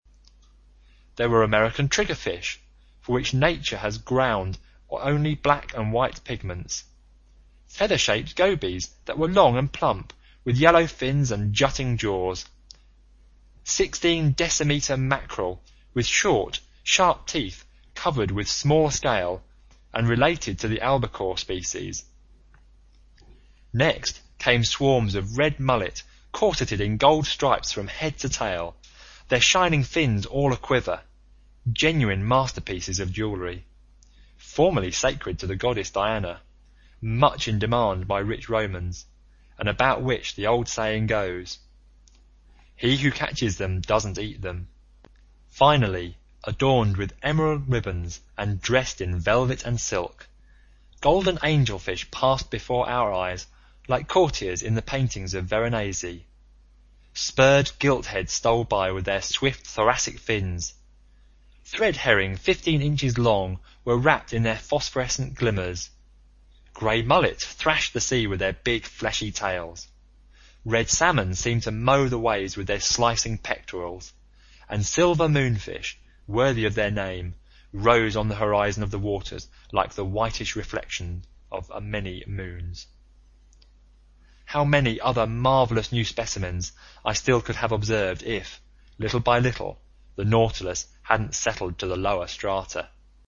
英语听书《海底两万里》第496期 第31章 章鱼(4) 听力文件下载—在线英语听力室
在线英语听力室英语听书《海底两万里》第496期 第31章 章鱼(4)的听力文件下载,《海底两万里》中英双语有声读物附MP3下载